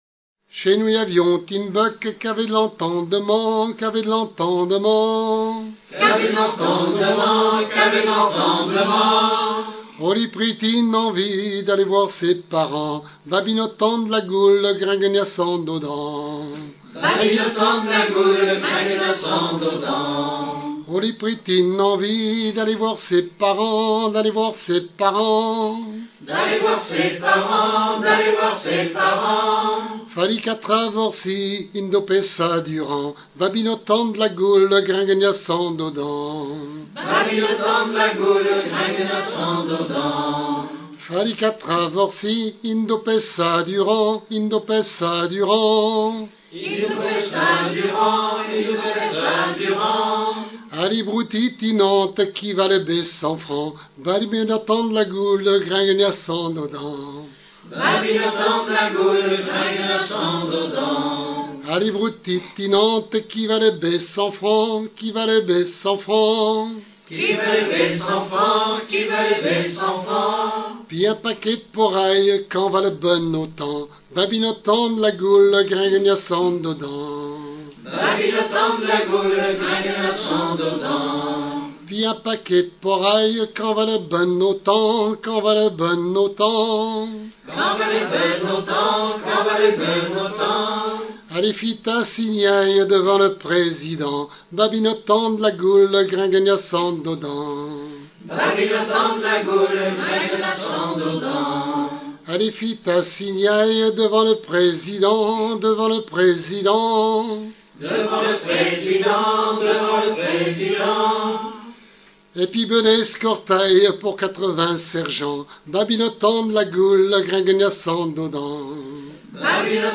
Genre énumérative
Pièce musicale éditée